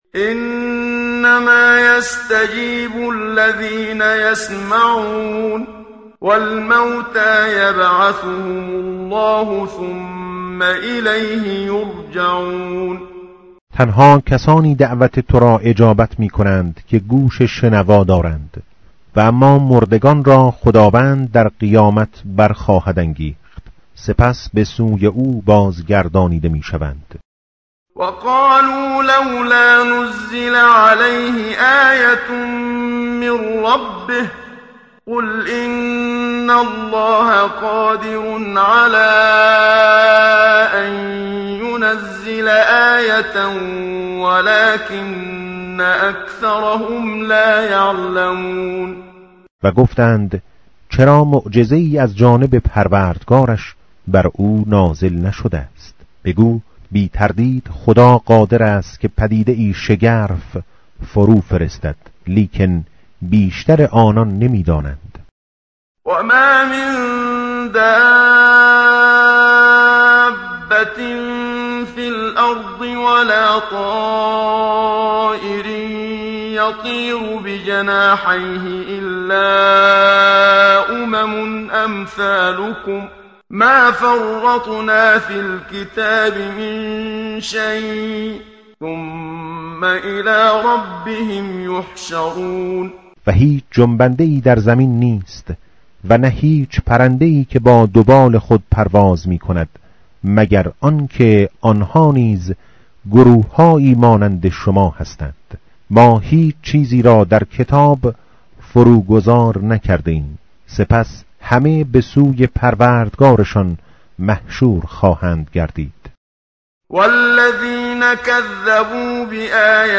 tartil_menshavi va tarjome_Page_132.mp3